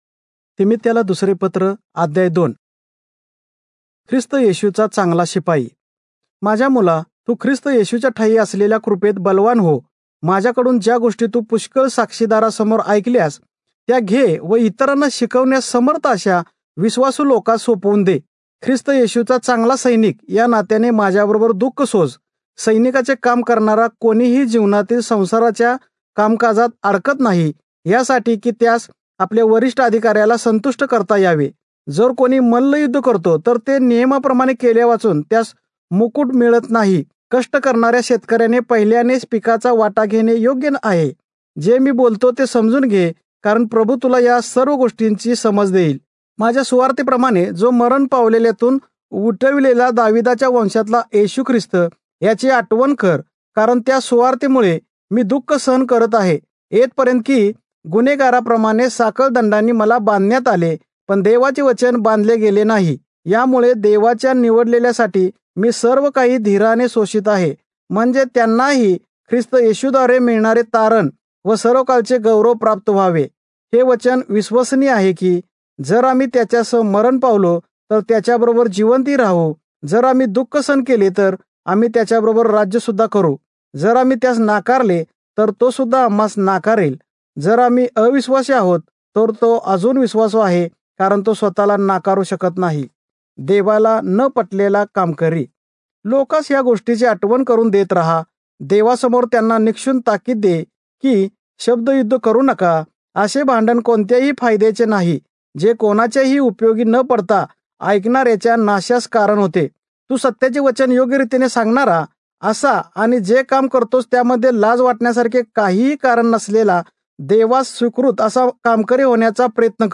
Marathi Audio Bible - 2-Timothy 2 in Irvmr bible version